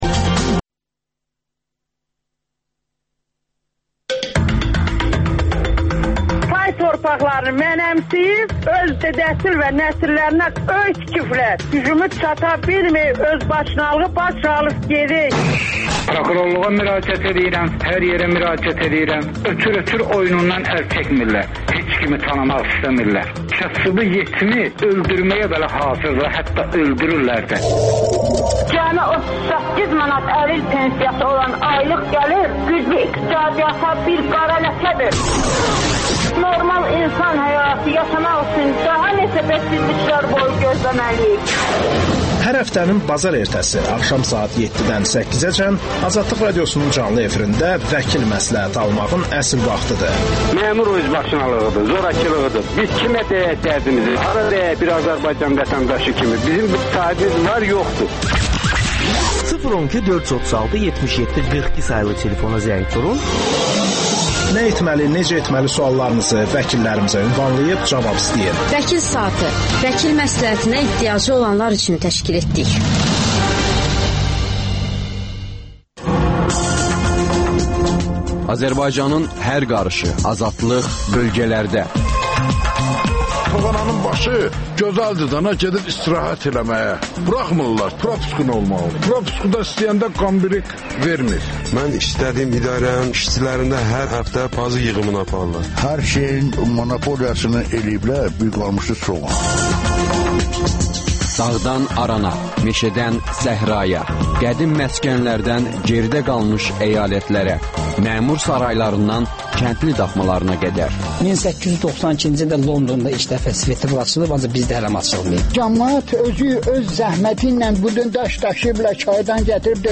AzadlıqRadiosunun müxbirləri ölkə və dünyadakı bu və başqa olaylardan canlı efirdə söz açırlar. Günün sualı: Azərbaycanda hansı qadağaya ehtiyac duyursunuz?